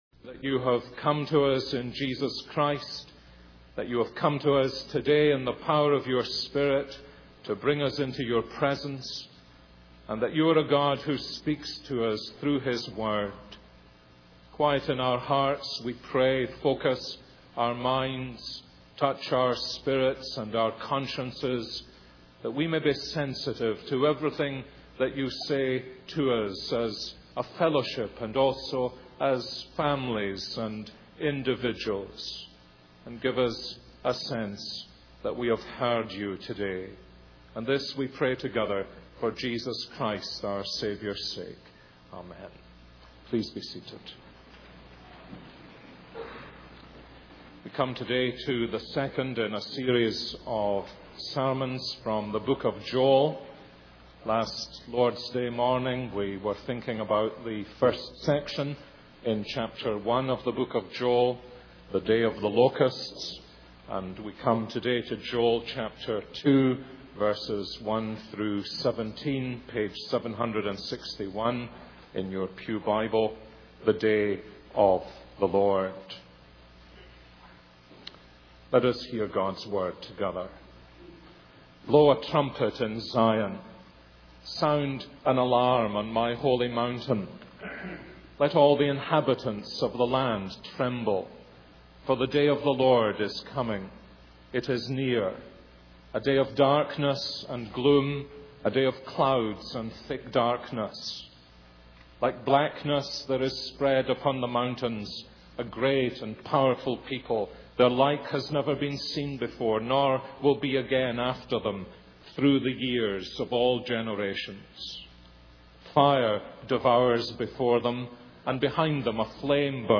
This is a sermon on Joel 2:1-17.